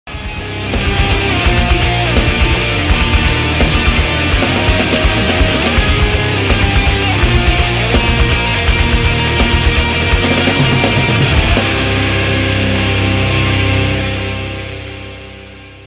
Dengan sound keras, riff tajam, sound effects free download